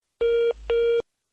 drain.mp3